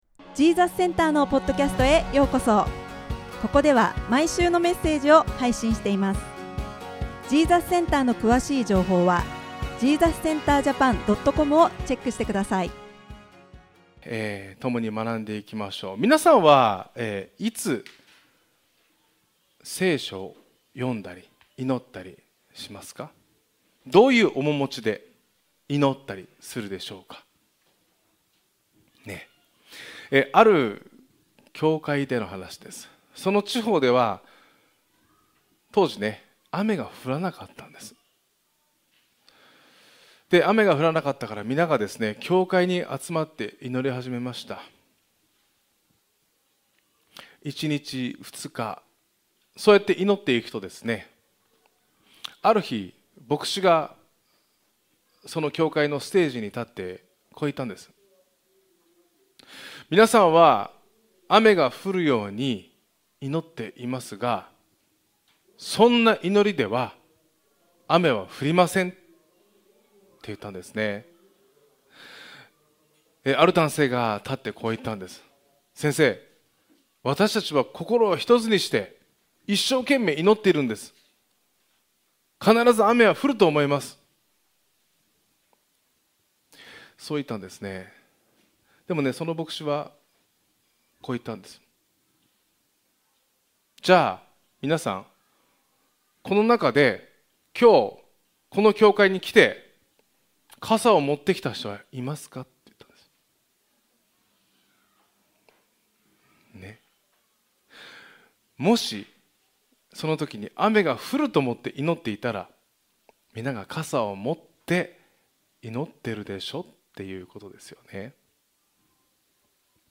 だれ ジーザスセンターの聖書のメッセージです。